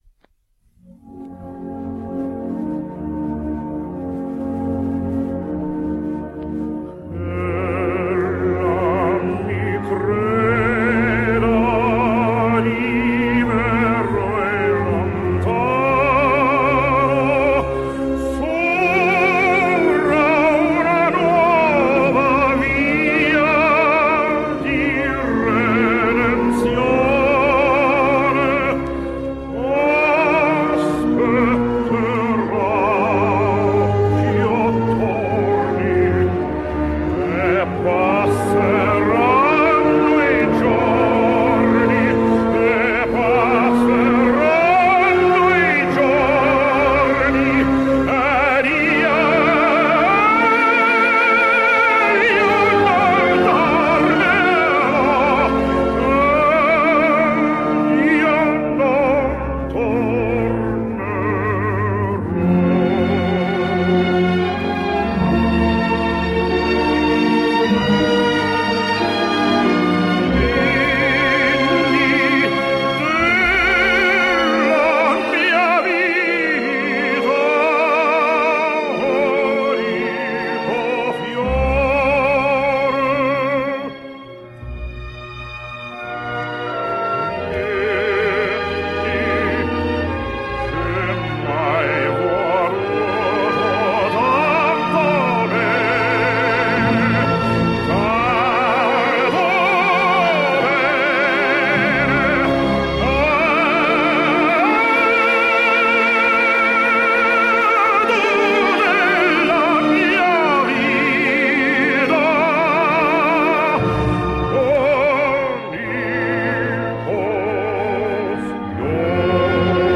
American Tenor.
Ch’Ella Me Creda / G-West – Jan Peerce